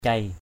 /ceɪ/ 1. (d.) cậu, em trai của mẹ = petit frère de la mère. uncle (younger brother of mother). mik cei m{K c] em trai của cha = petit frère...